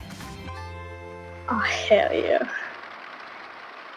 chatter-sub-notif.mp3